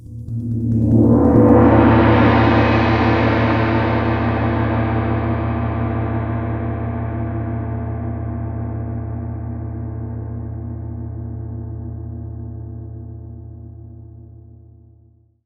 Index of /90_sSampleCDs/Partition E/MIXED GONGS